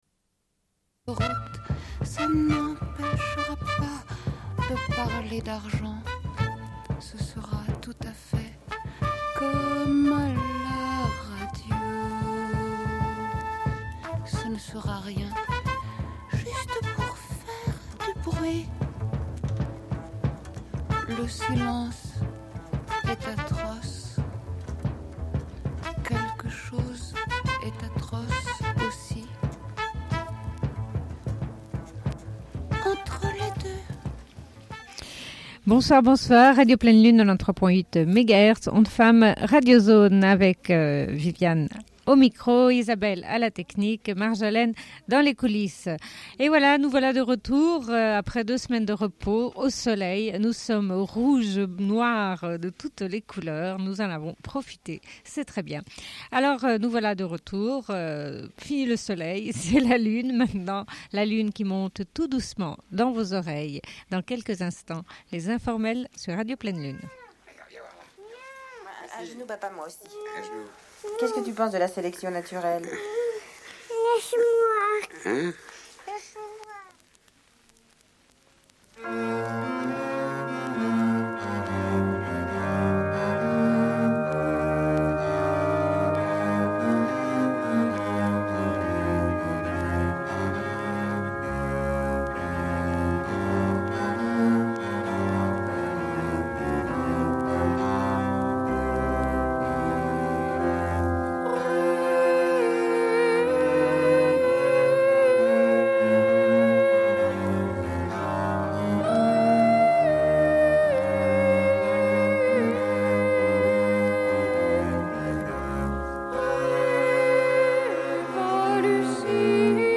Bulletin d'information de Radio Pleine Lune du 21.04.1993 - Archives contestataires